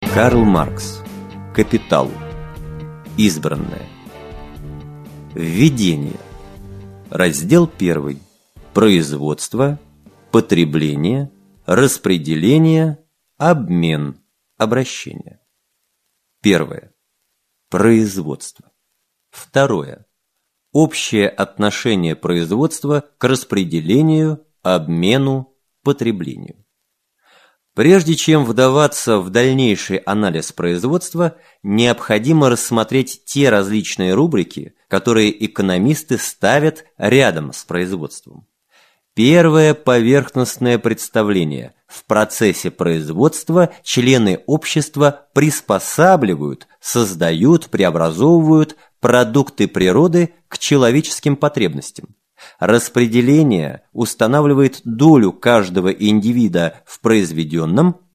Аудиокнига Капитал. Избранное | Библиотека аудиокниг